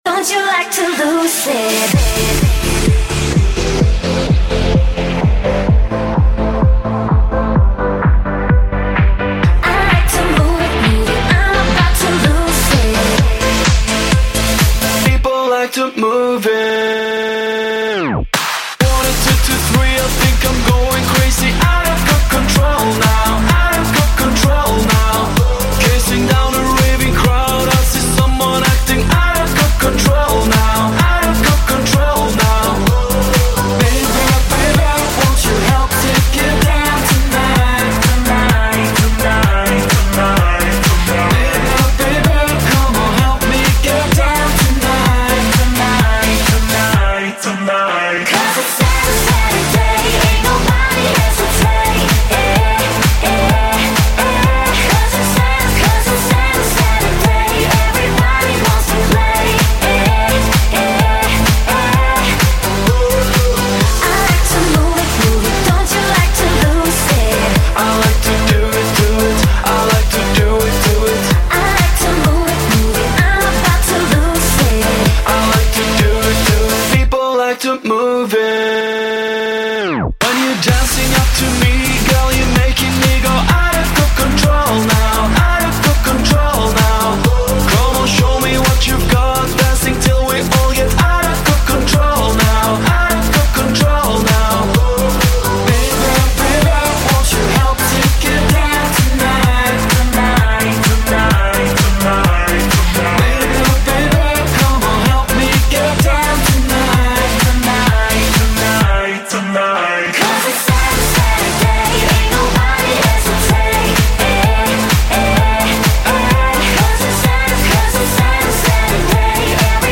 Жанр-клуб, club